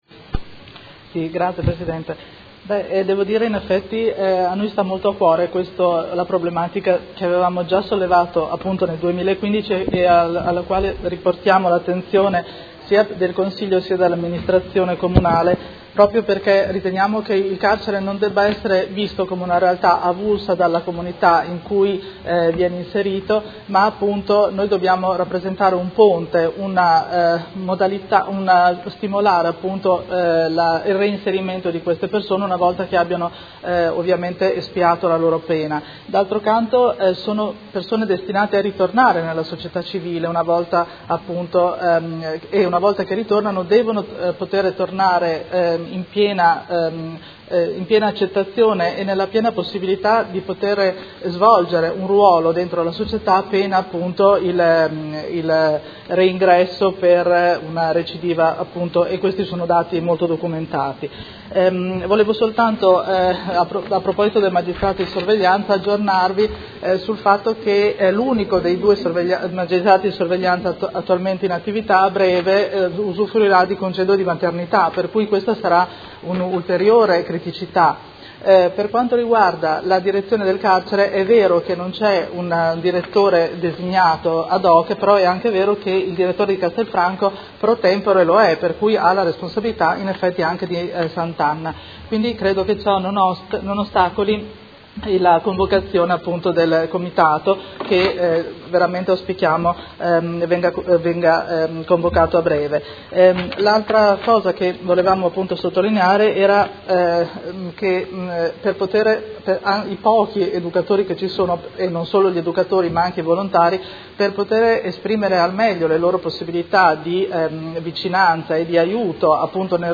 Seduta del 26/03/2018 Interrogazione delle Consigliere Pacchioni e Baracchi (PD) avente per oggetto: Situazione della popolazione carceraria modenese.